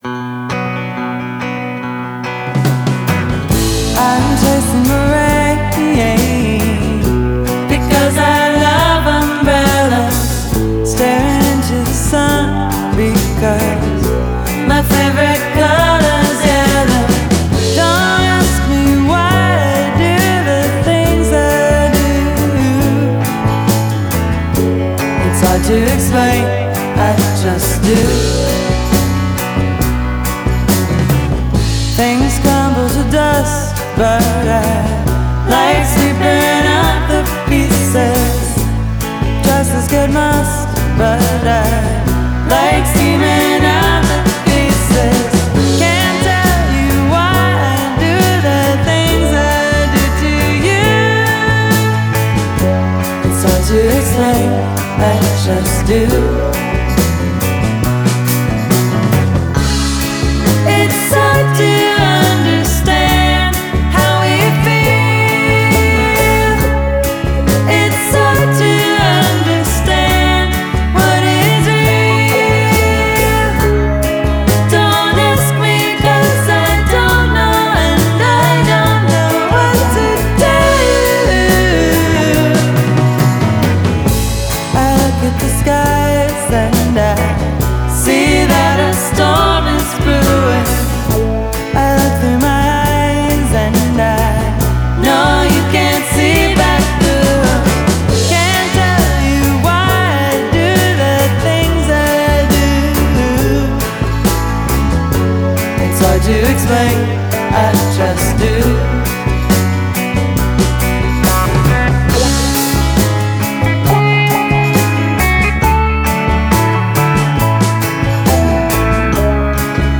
Genre: Indie Folk